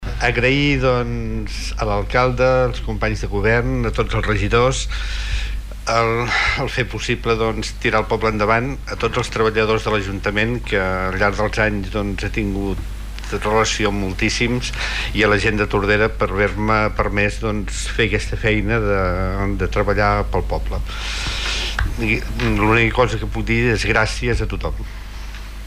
Un ple extraordinari ràpid va tancar ahir a la tarda oficialment el mandat municipal 2015 a 2019. En un ambient distès i menys oficial, ahir es va aprofitar per acomiadar els regidors que no seguiran en el consistori.
El regidor d’Obres i Serveis i Pagesia, Carles Aulet va voler agrair a tothom el seu suport per desenvolupar la seva tasca.